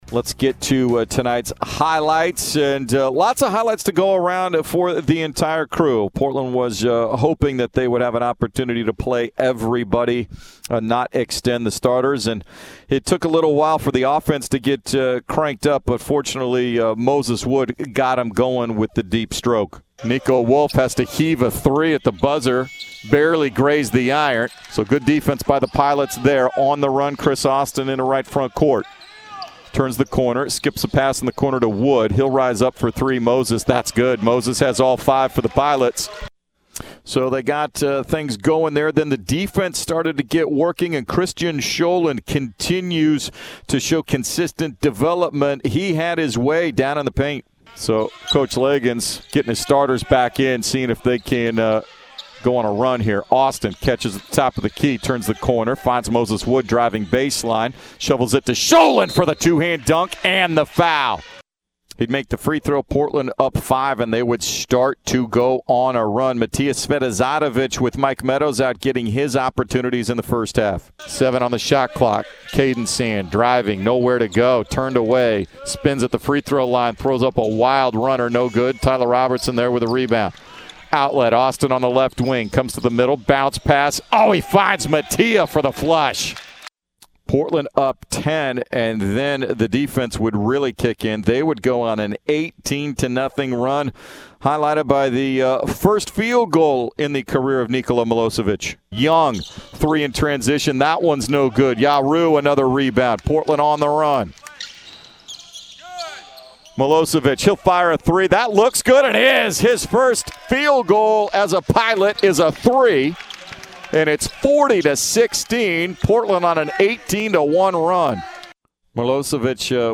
Radio Highlights vs. Bushnell
February 15, 2022 Radio highlights from Portland's 84-39 win against Bushnell on 910 ESPN Portland (KMTT).